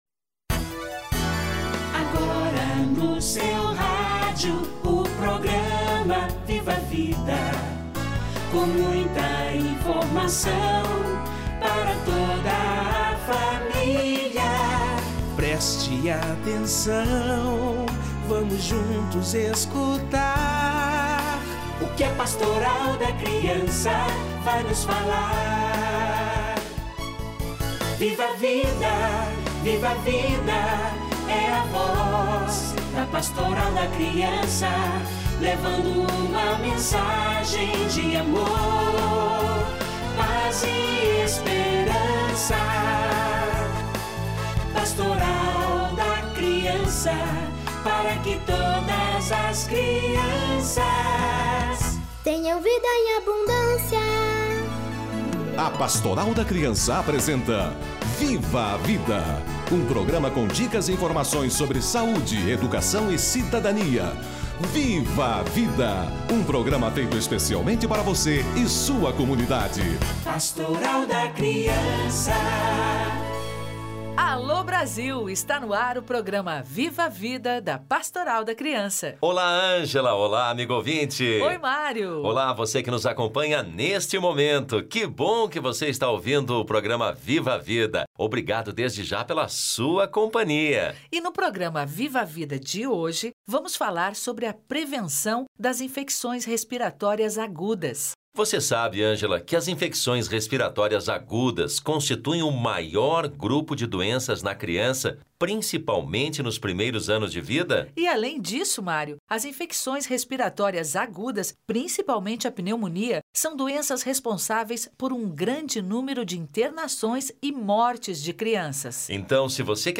Infecções respiratórias agudas - Entrevista